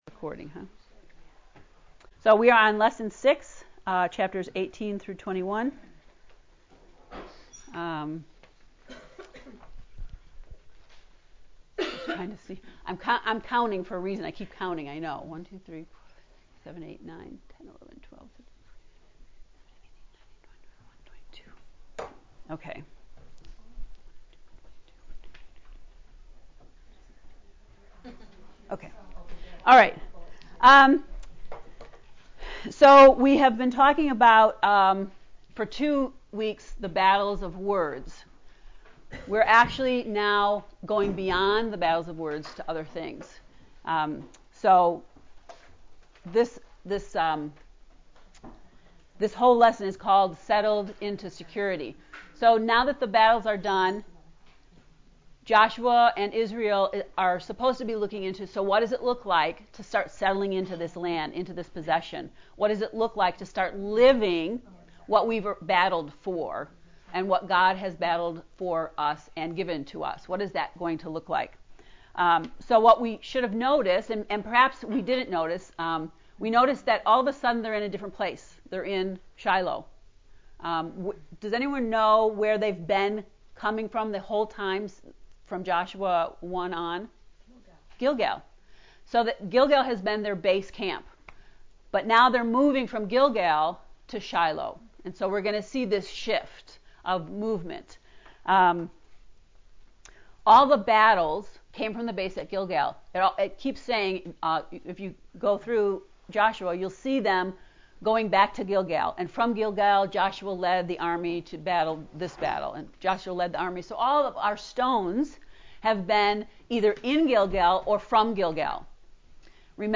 To listen to the lesson 6 lecture, “Settling Into Safety”, click below: